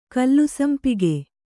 ♪ kallusampige